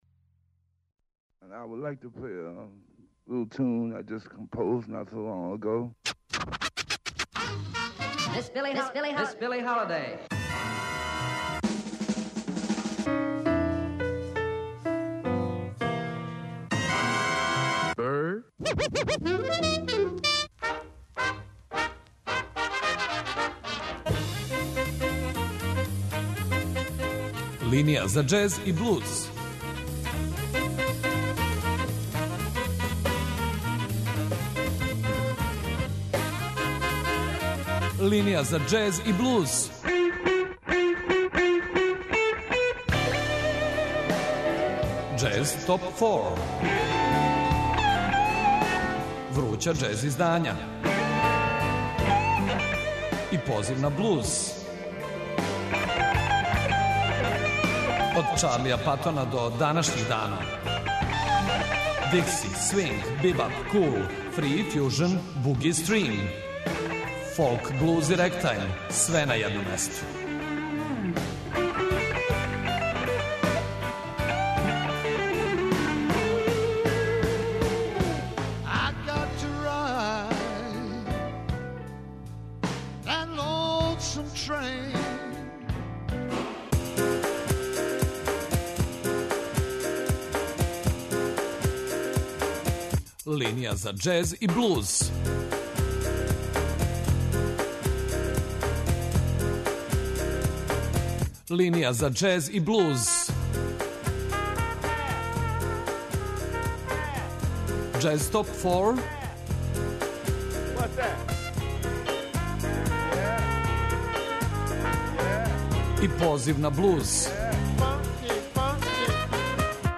Свако издање биће представљено у оквиру 25-минутног блока, уз кратку причу и музику са албума.